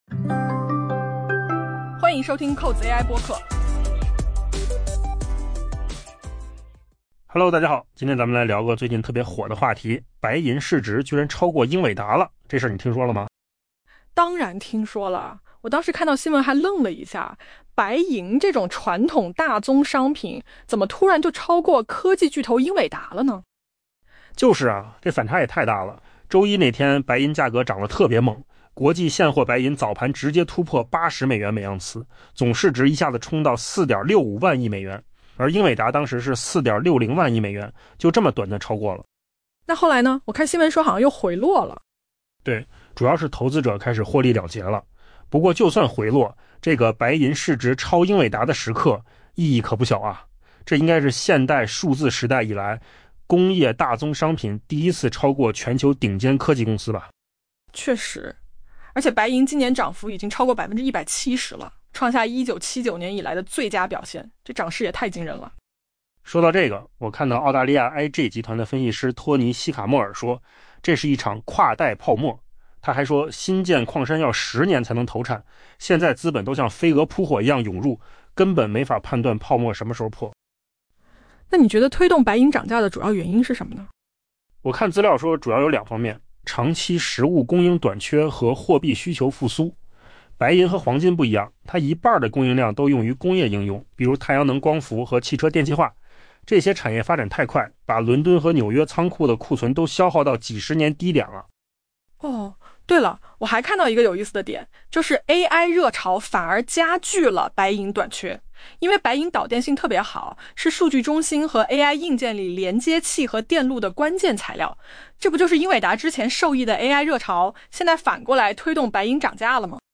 AI播客：换个方式听播客 下载mp3
音频由扣子空间生成